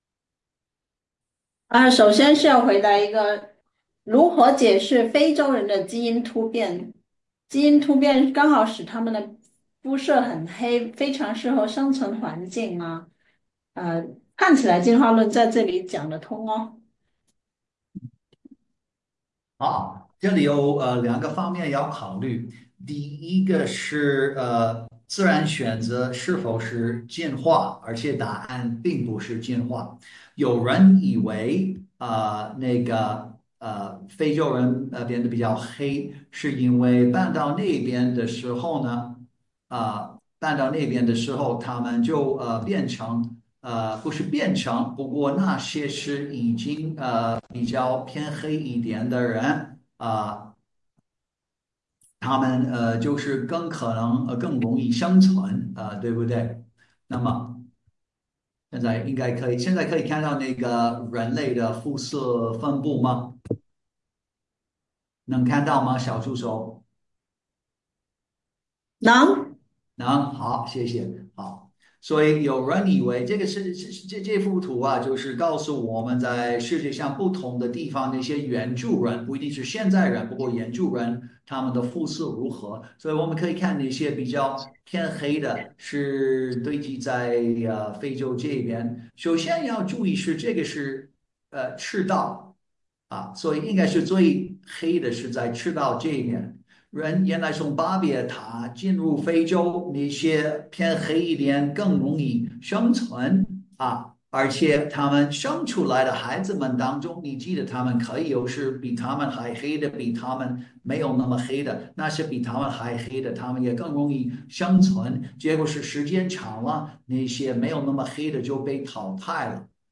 如果全人类都是亚当和夏娃的后代，不同人种从何而来？本期月度讲座将从科学、历史及圣经记载解读真相： 不同人种来自基因的变异而不是进化 主流遗传学已证明：全人类源自同一位女性和同一位男性。